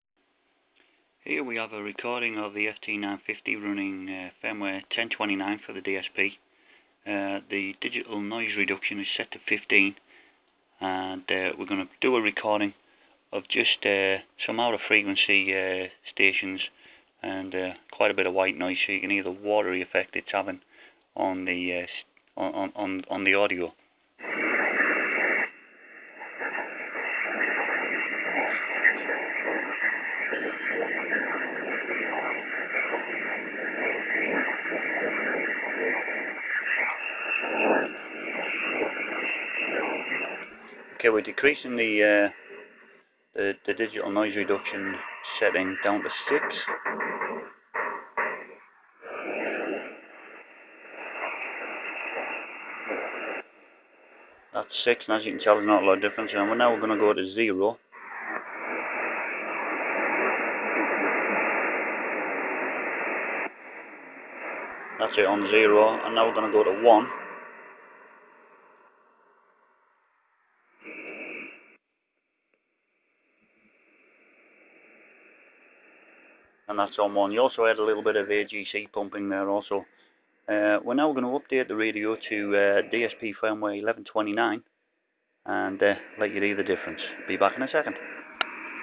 Gone is the awful distortion when using Digital Noise Reduction and/or Auto Notch.
Below is an image of the rig showing the new firmware, in fact it is old, because it is now on DSP version 11.39. Listen to the audio files to hear the difference the update makes.
FT-950 DSP 10.29 Audio.